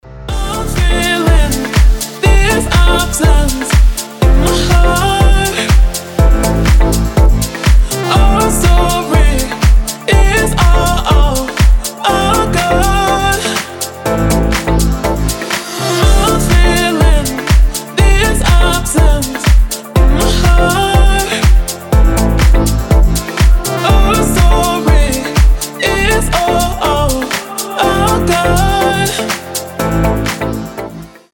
• Качество: 320, Stereo
ритмичные
женский вокал
deep house
nu disco
Приятная дип-хаус музыка